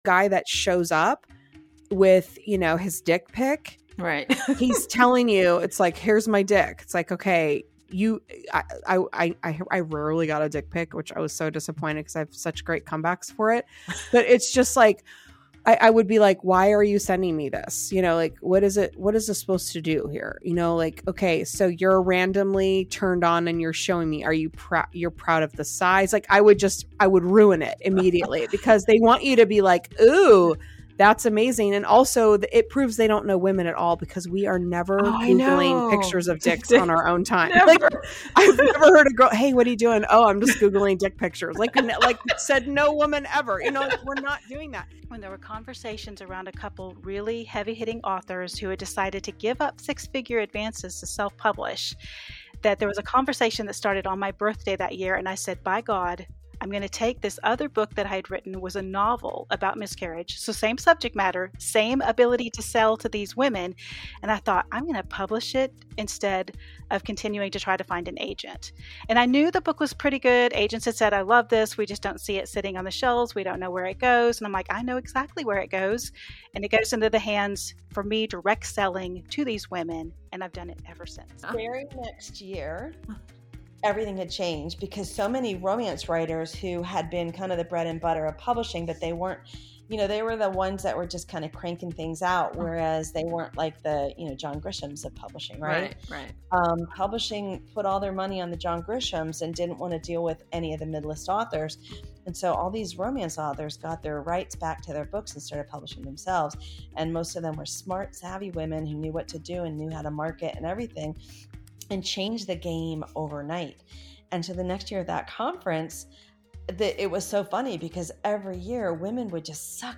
A Time to Thrill - Trailer